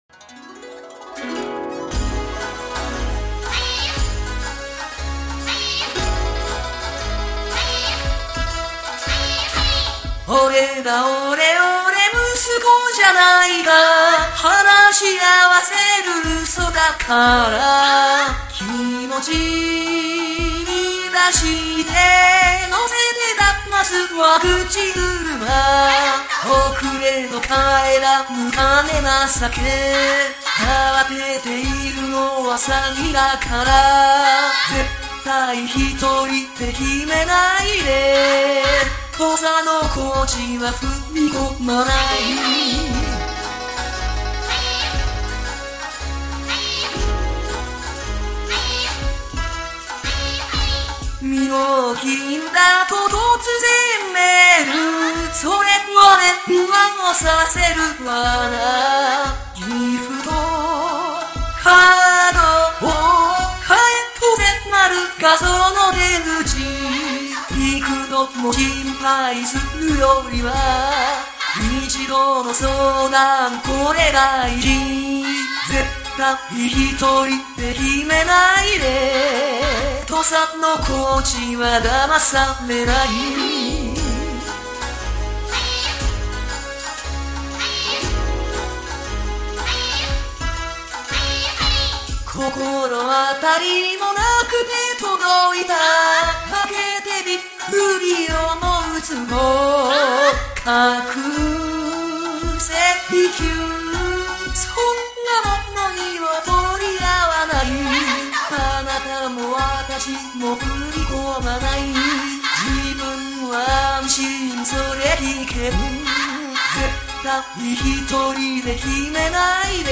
すか？各種イベントで、歌と踊りに合わせて防犯の輪が広がることへの期待が高まっています。